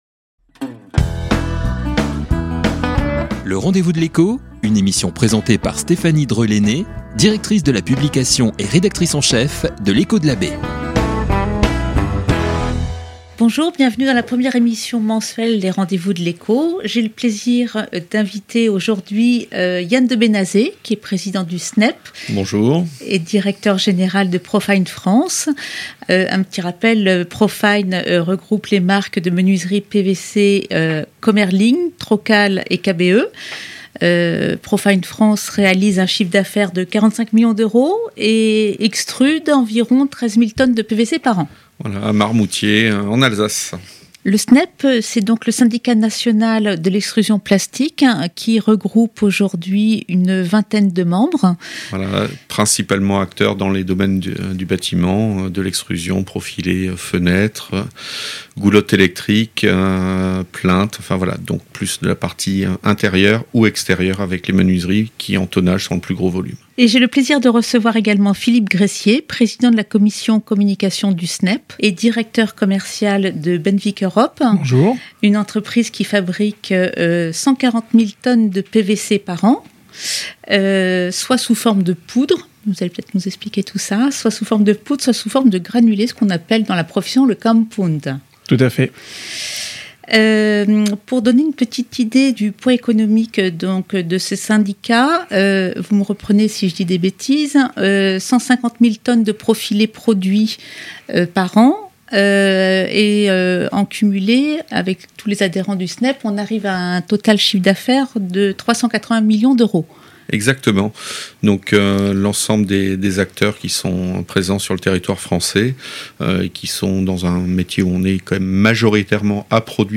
Les rendez-vous de L'Echo - Interview SNEP - Batiradio - L'Echo de la baie